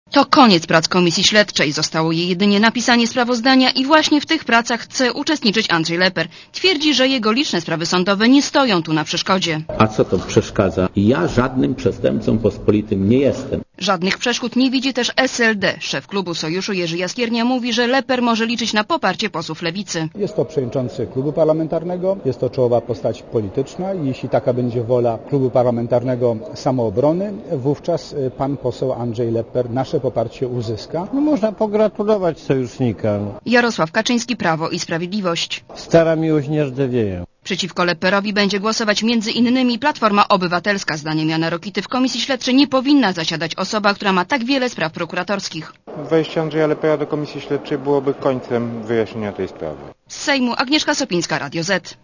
Posluchaj relacji reporterki Radia Zet (202 KB)